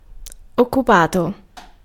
Ääntäminen
Tuntematon aksentti: IPA : /ˈbɪ.zi/